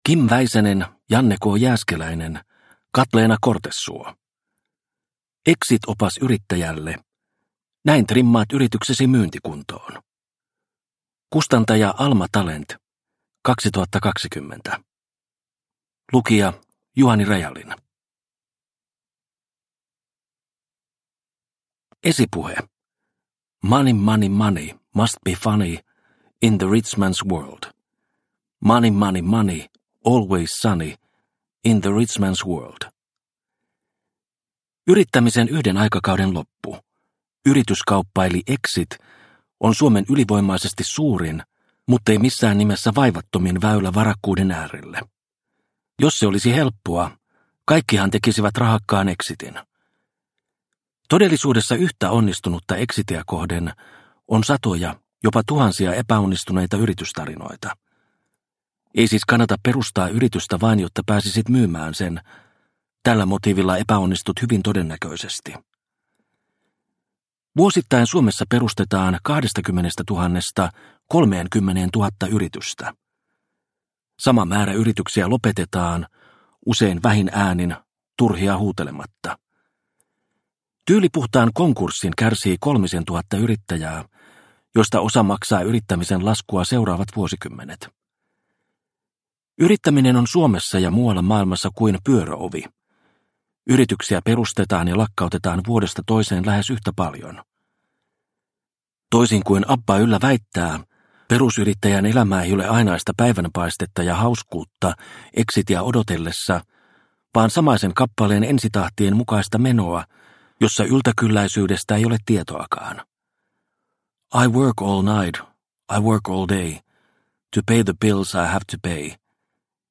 Exit-opas yrittäjälle – Ljudbok – Laddas ner